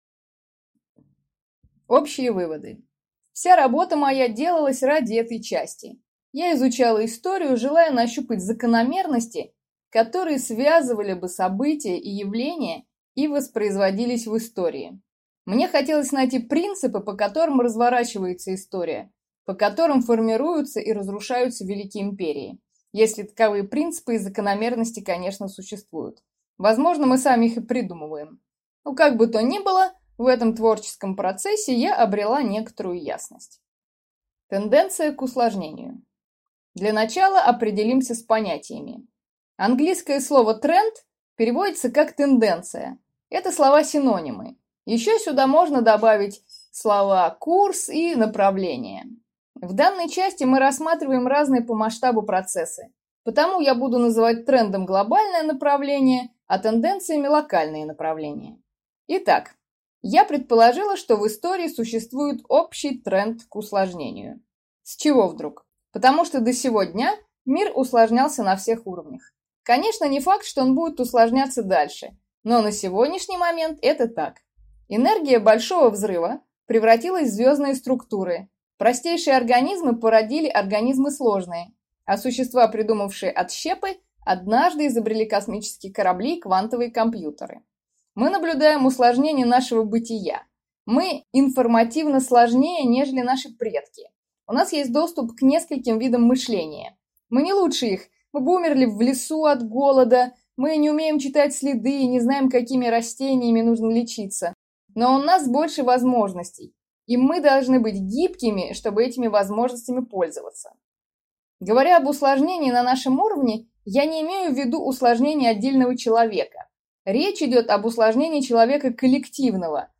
Аудиокнига Выводы о Процессе | Библиотека аудиокниг